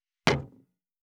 189,テーブル等に物を置く,食器,グラス,コップ,工具,小物,雑貨,コトン,トン,ゴト,ポン,ガシャン,
コップ効果音物を置く